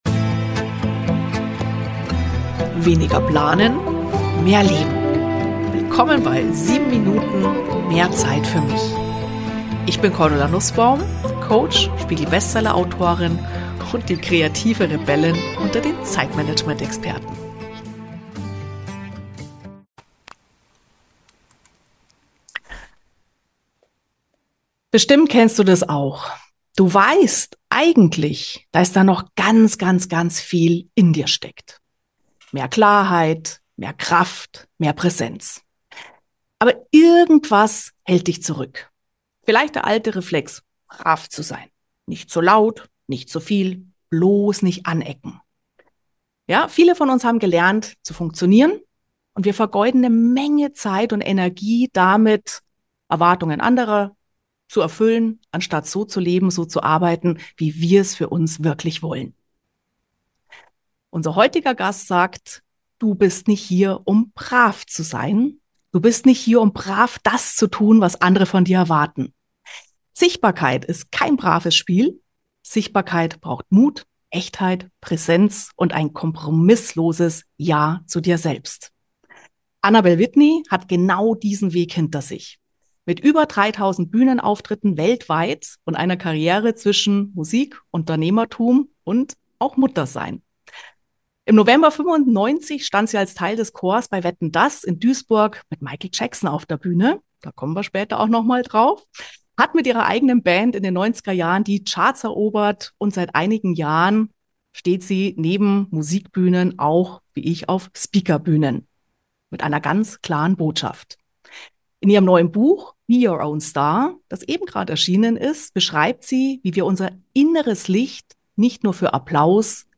Wie viel Zeit kostet Dich Deine »Maske?« | Der Talk